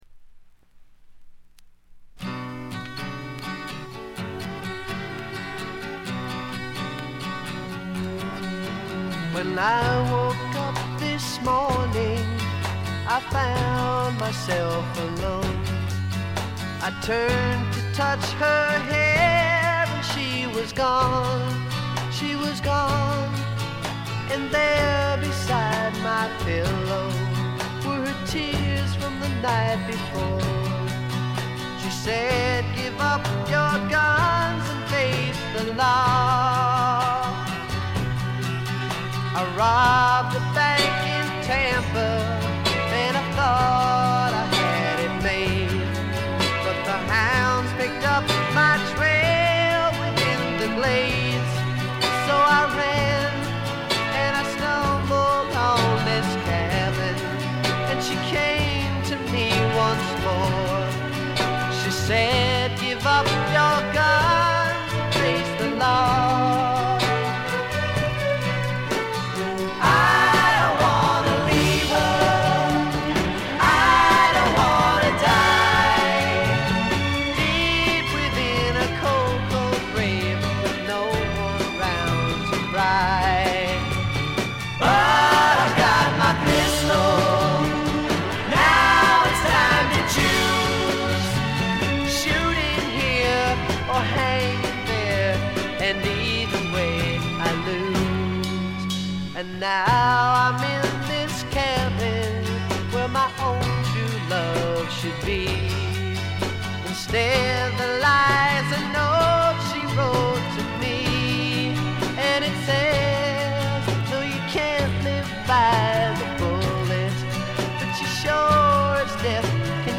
部分試聴ですがほとんどノイズ感無し。
試聴曲は現品からの取り込み音源です。